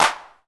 DrClap1.wav